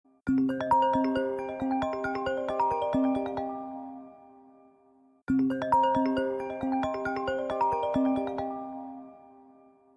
la suoneria del nuovo iPhone!